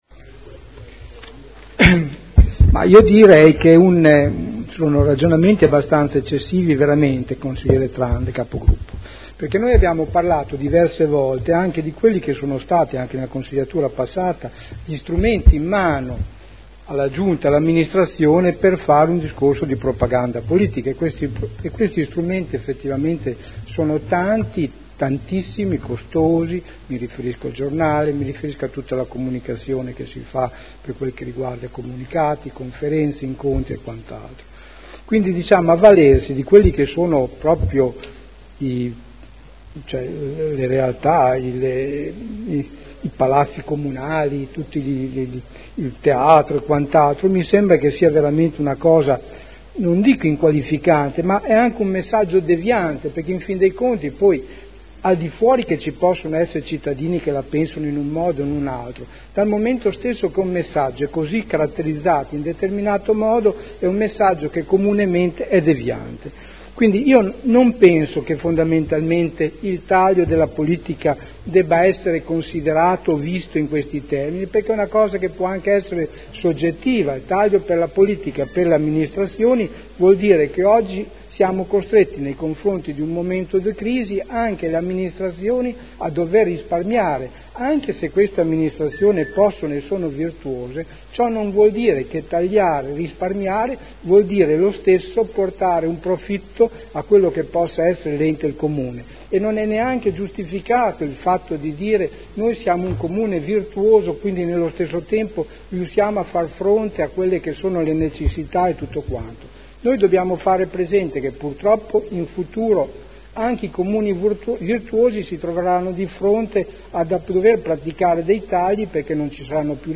Seduta del 23/05/2011.